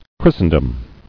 [Chris·ten·dom]